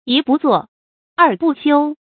注音：ㄧ ㄅㄨˋ ㄗㄨㄛˋ ，ㄦˋ ㄅㄨˋ ㄒㄧㄨ
讀音讀法：